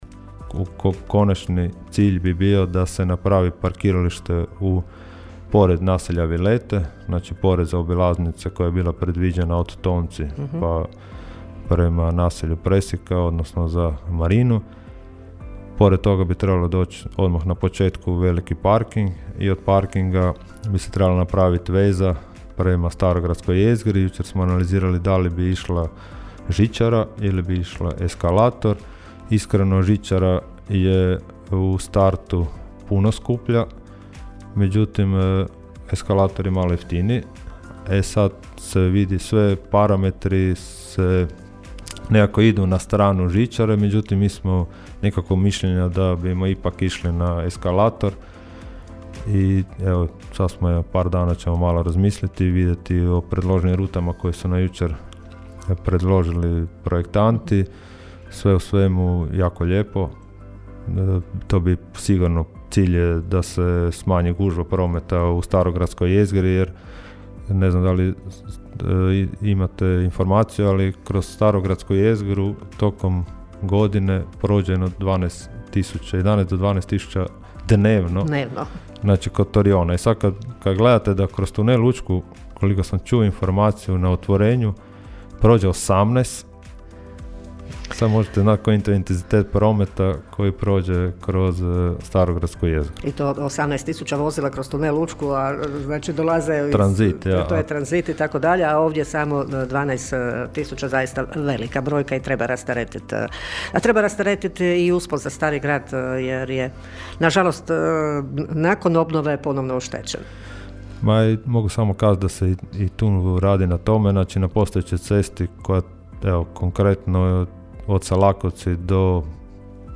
U subotnjim Gradskim minutama gradonačelnik Donald Blašković govorio je o sastancima koji su održani, a kako bi se našlo rješenje za što manje automobila i autobusa u labinskom starom gradu: (
ton – Donald Blašković).